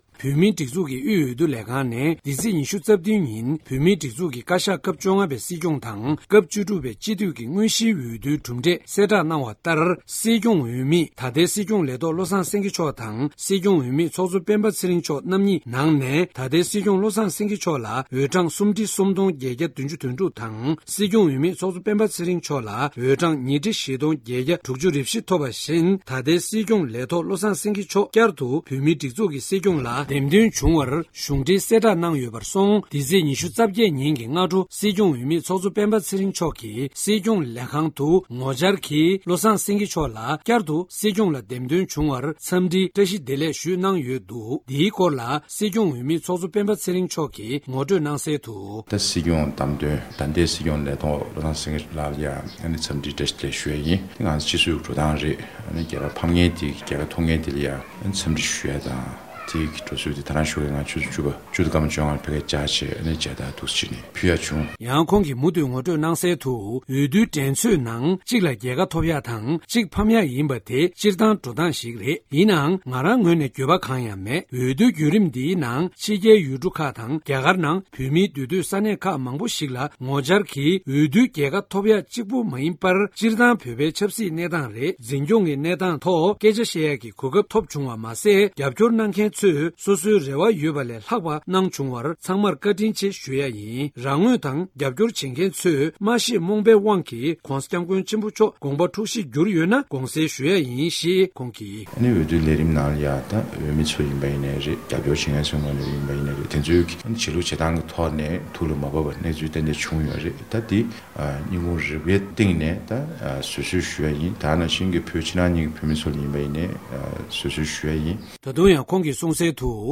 ས་གནས་ནས་བཏང་བའི་གནས་ཚུལ་ལ་གསན་རོགས་ཞུ༎